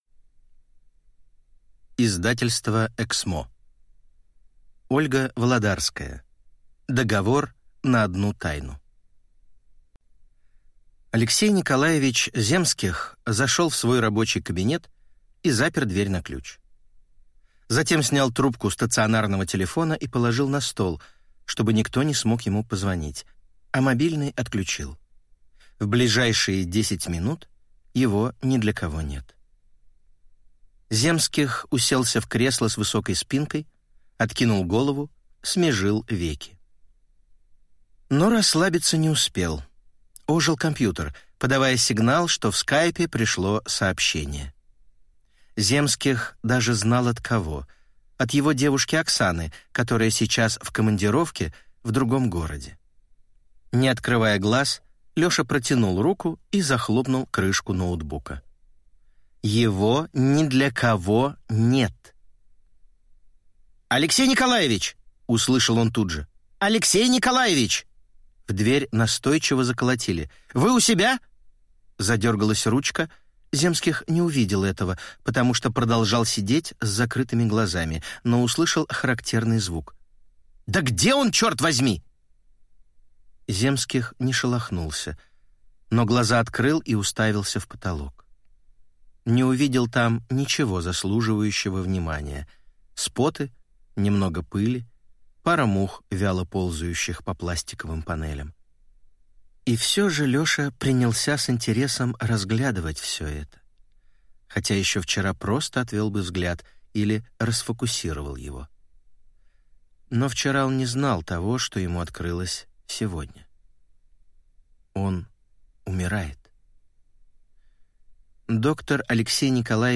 Аудиокнига Договор на одну тайну | Библиотека аудиокниг
Прослушать и бесплатно скачать фрагмент аудиокниги